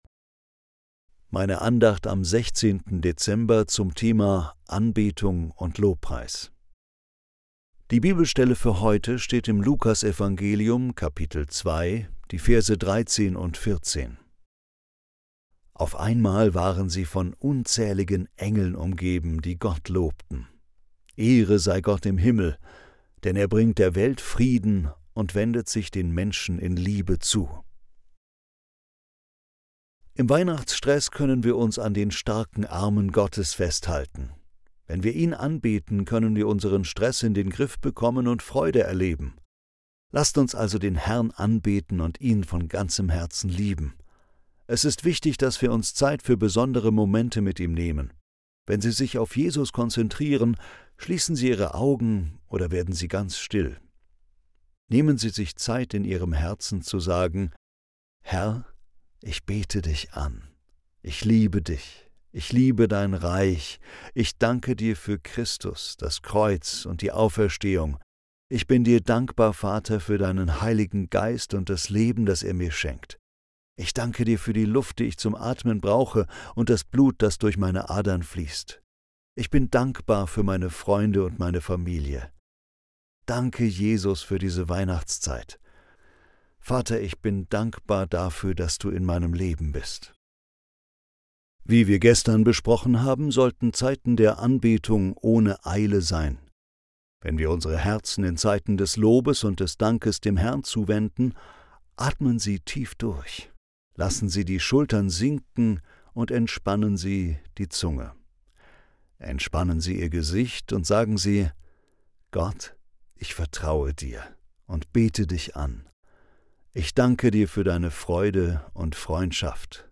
Andacht zum 16. Dezember